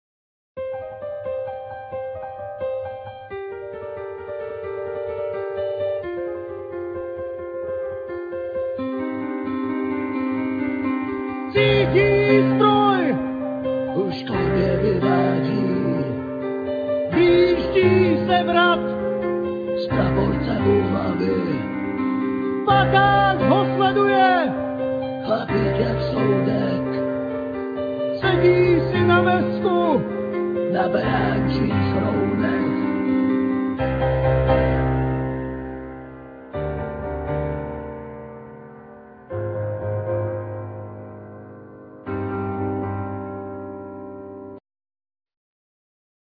Piano,Keyboards,Trumpet,Vocal,whistling
Lead Vocal,Trumpet
Drums,Accordion,Vocal
Bass guitar
Alt sax,Clarinet
Cello
Cimbal
Backing vocal,Whisper